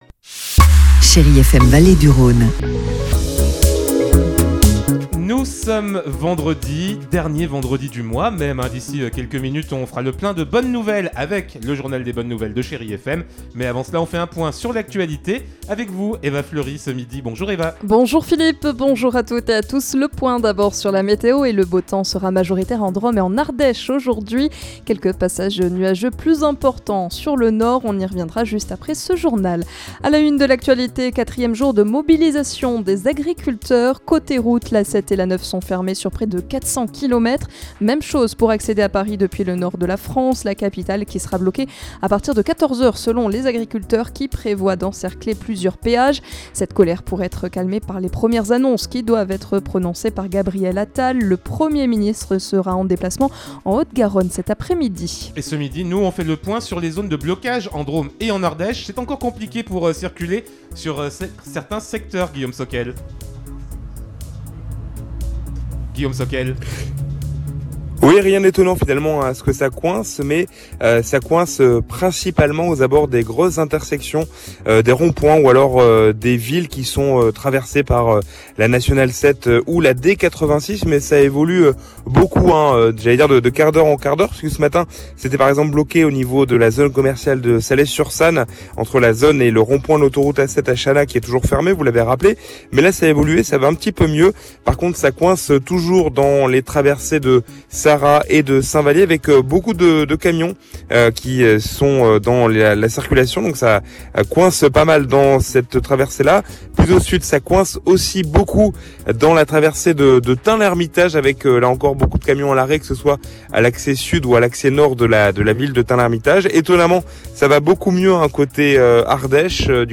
in Journal du Jour - Flash
Vendredi 26 janvier : Le journal de 12h